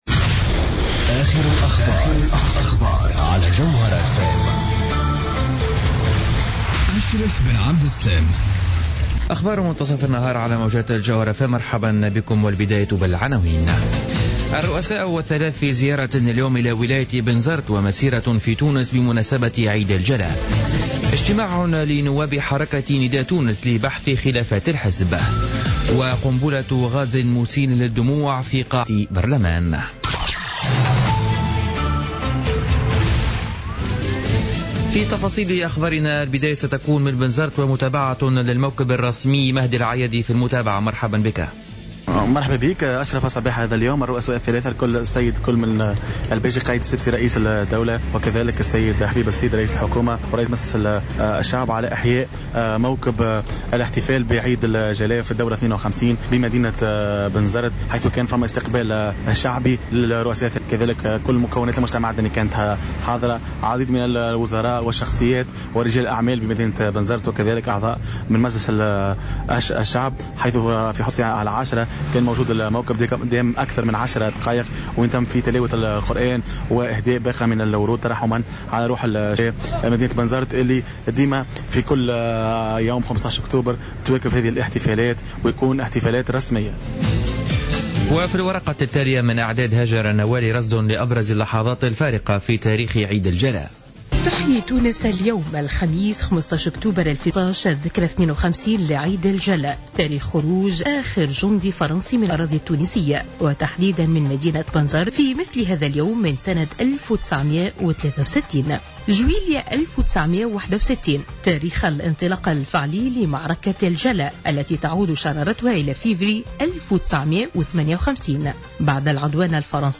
نشرة أخبار منتصف النهار ليوم الخميس 15 أكتوبر 2015